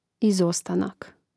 izòstanak izostanak